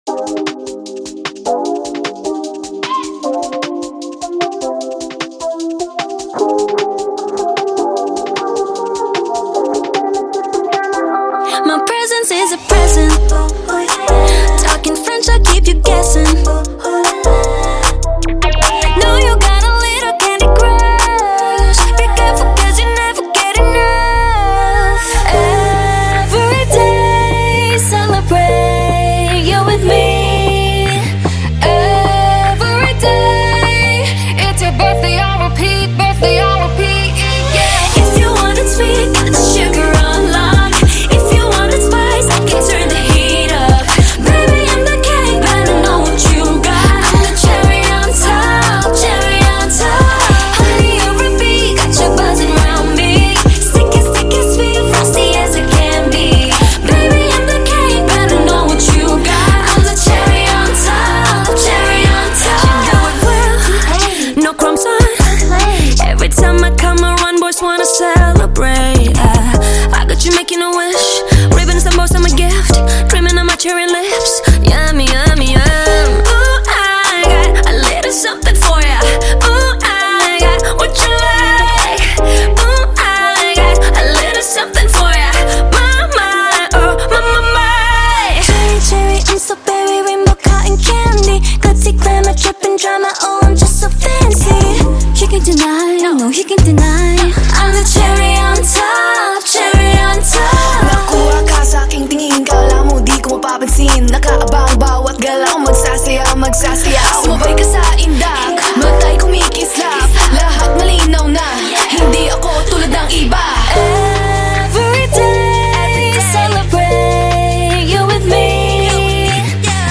Label Dance